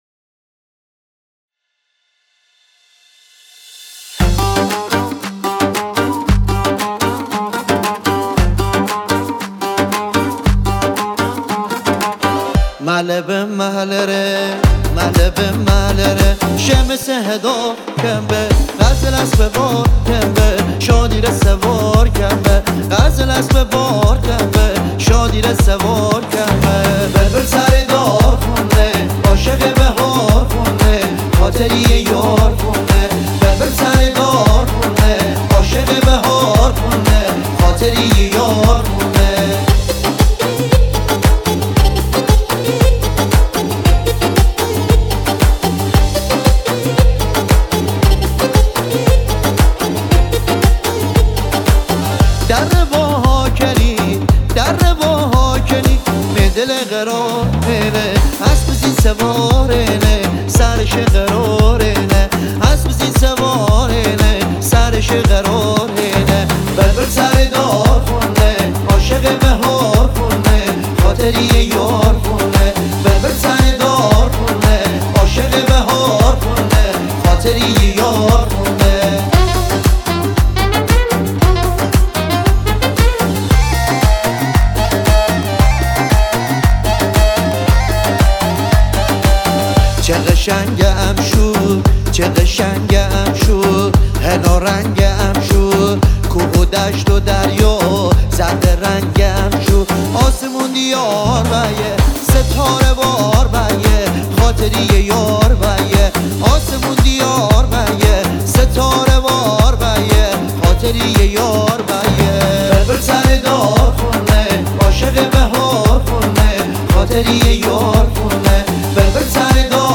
سنتی
آهنگ سنتی مازندرانی